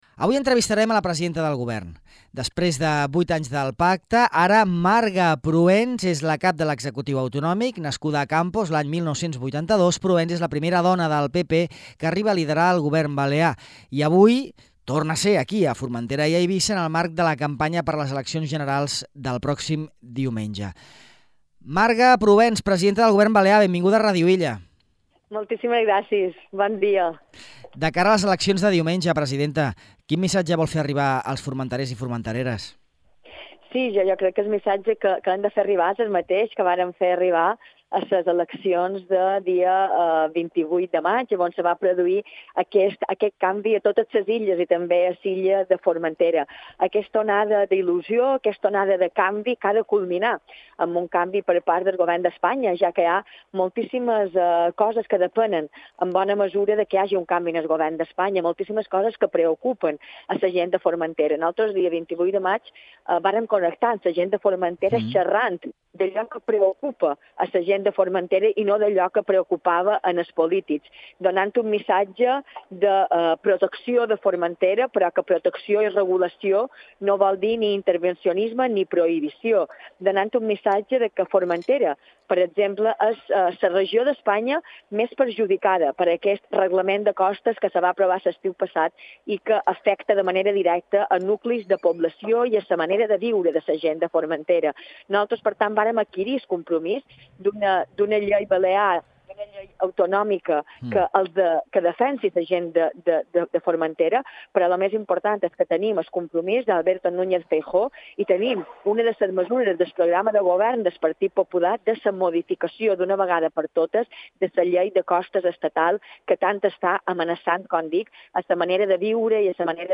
La presidenta del Govern balear, Marga Prohens, ha atès a Ràdio Illa en la visita a Formentera que ha portat a terme avui, en el marc de la campanya per a les Eleccions Generals del 23 de juliol.